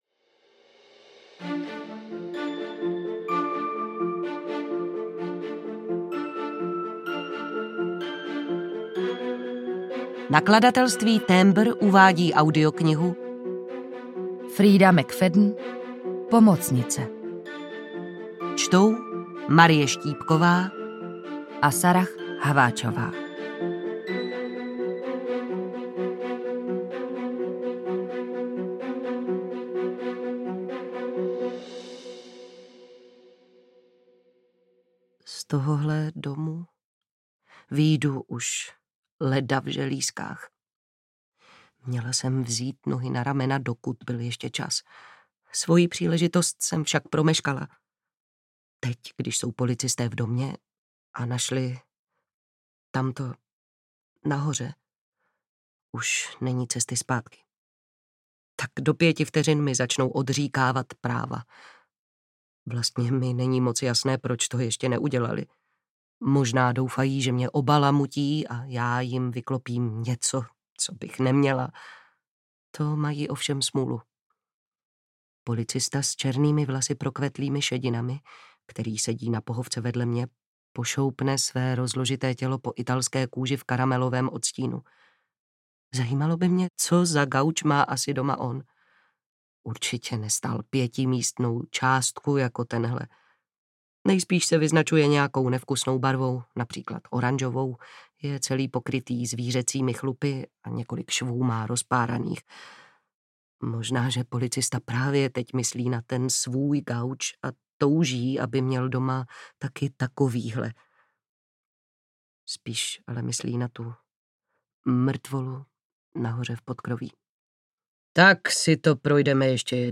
Pomocnice audiokniha
Ukázka z knihy